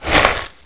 1 channel
sand1tap.mp3